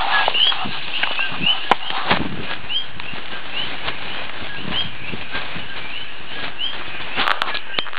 カエルの合唱が始まりつつあった。
でもそれは我々が知っているそれとは違って音階を持っていた。
昼に単発で聴いていた時には鳥の鳴き声と信じて疑わなかった美声。
カエルの合唱 63kb.wav
鳴き声が「コーキー」と聴こえるそのカエルの名は「Coqui frog」。
coqui_frog.WAV